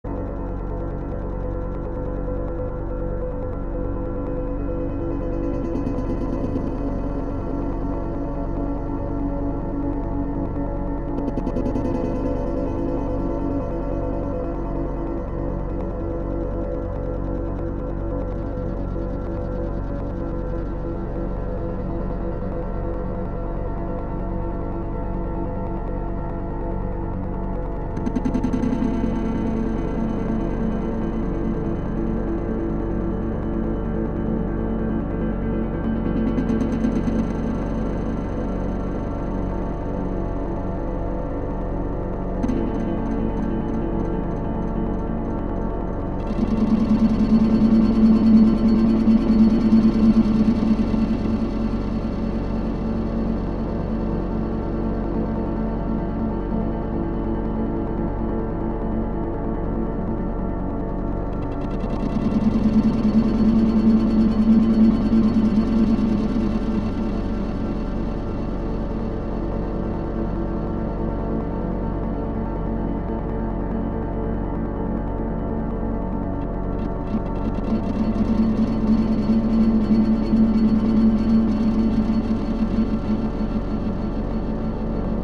Just messing about with guitar and DT